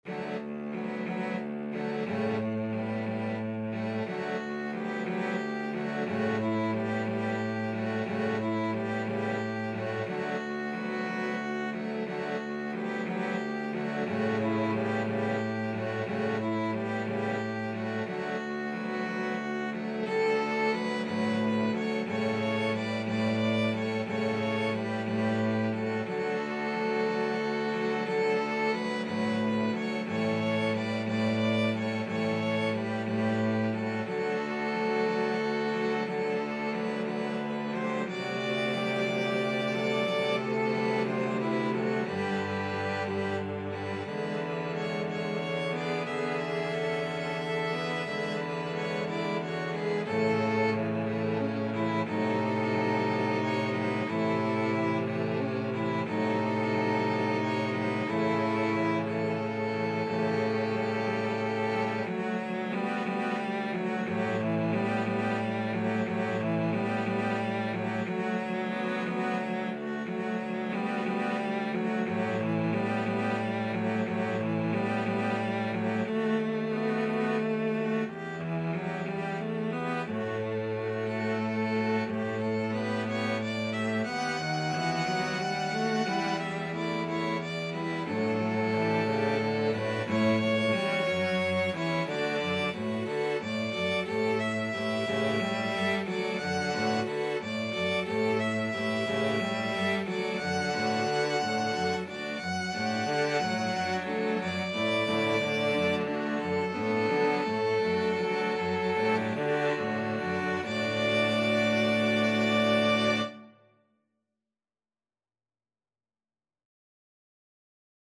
ViolinFlute
Cello
6/8 (View more 6/8 Music)
D major (Sounding Pitch) (View more D major Music for Flexible Mixed Ensemble - 5 Players )
Moderato .=60
Flexible Mixed Ensemble - 5 Players  (View more Easy Flexible Mixed Ensemble - 5 Players Music)
Classical (View more Classical Flexible Mixed Ensemble - 5 Players Music)